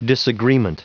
Prononciation du mot disagreement en anglais (fichier audio)
Prononciation du mot : disagreement